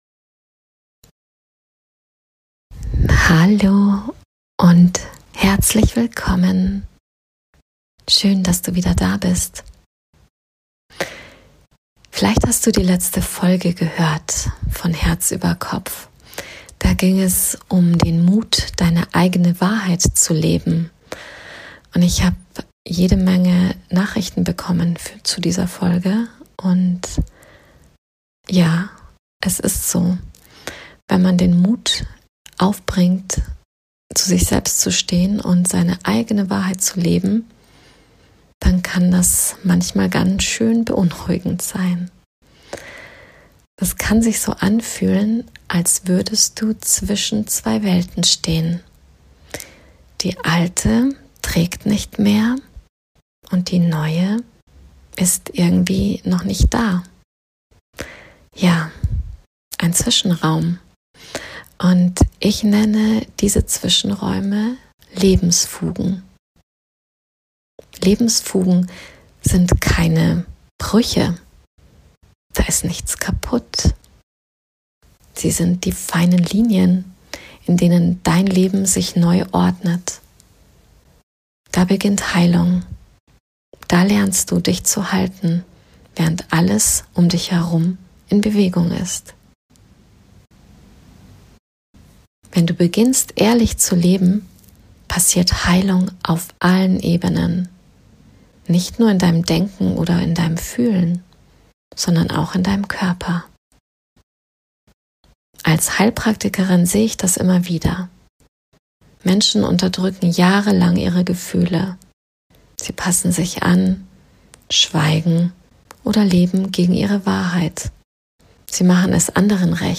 In dieser Folge erfährst Du, warum Dein Nervensystem auf Veränderung reagiert, wie Du Dich über Atem und Bewegung selbst regulierst, und warum Fühlen der Schlüssel zu echter Heilung ist. Mit einer geführten Meditation, die Dich erdet, beruhigt und Dich sanft in Deine innere Stabilität zurückführt.